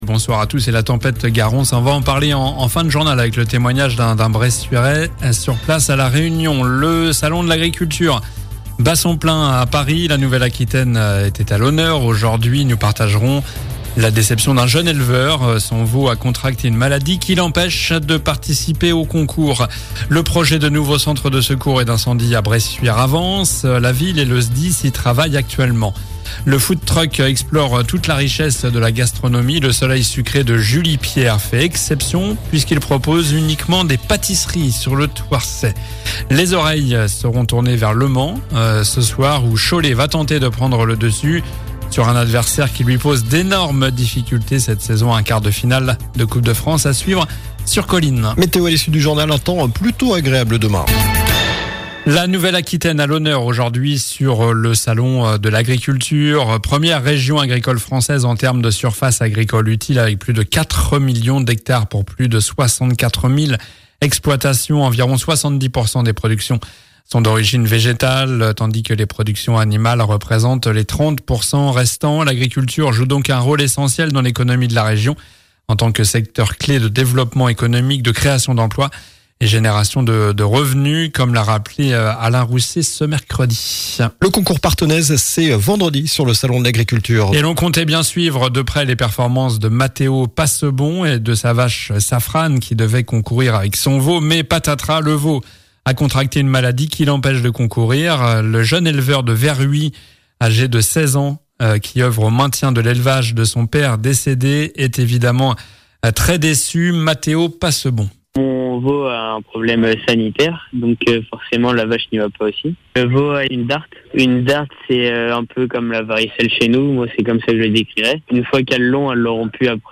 Journal du mercredi 26 février (soir)